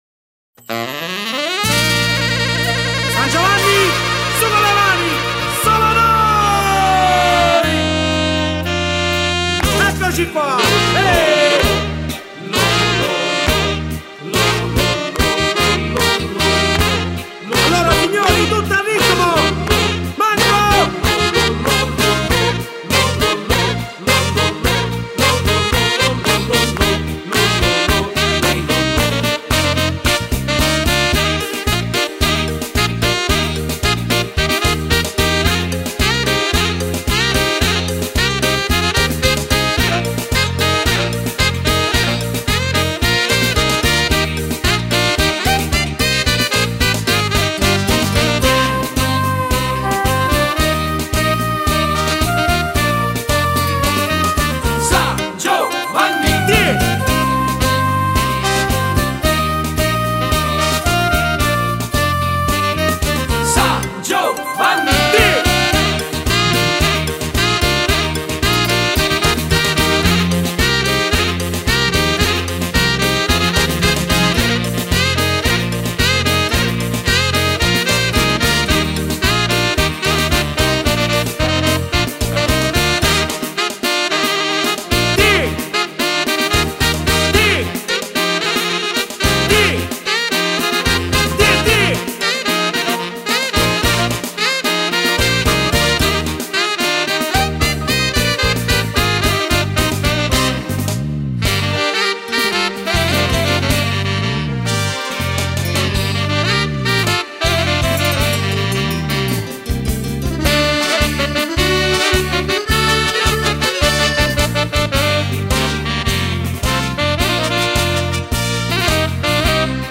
Canzone d'occasione